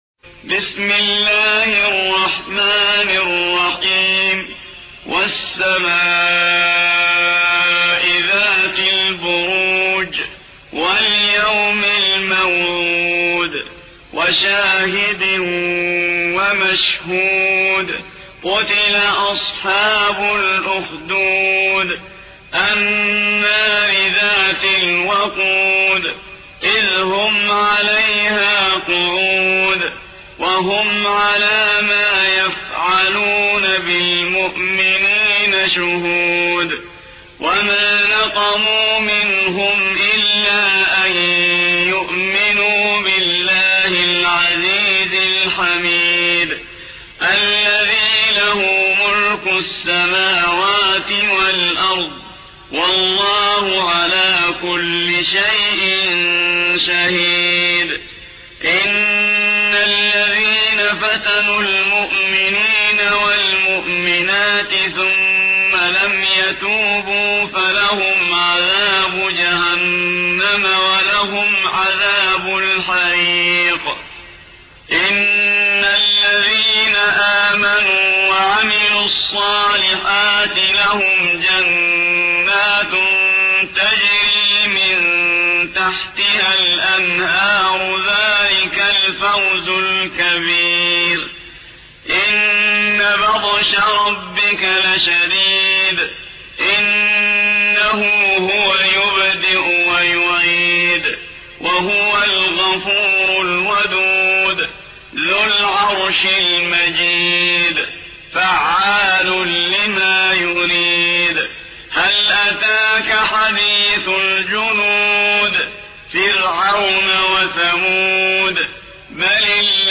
85. سورة البروج / القارئ